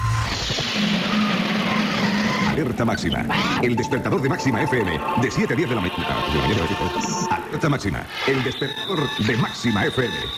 Indicatiu del programa